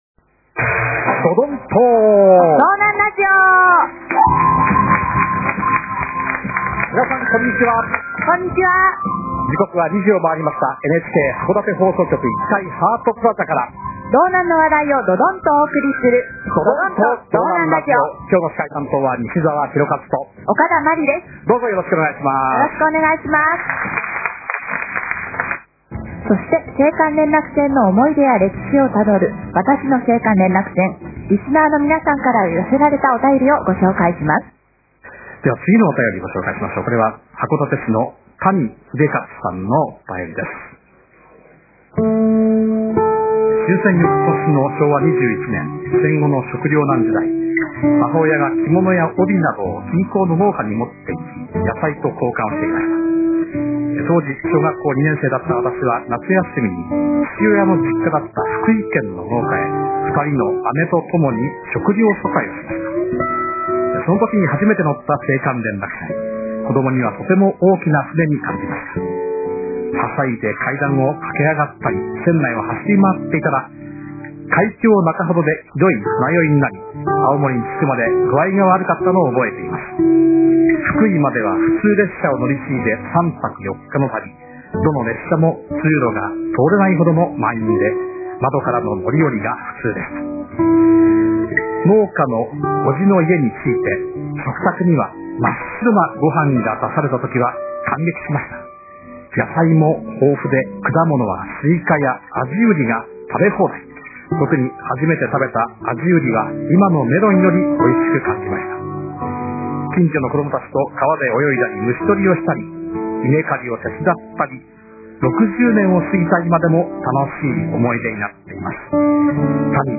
NHK函館放送局「どどんと道南ラジオ」で放送される。
ギターのBGM入りで拙い文章を上手く読んでいただきました。
聞けない場合はこちら（音質が悪くなります）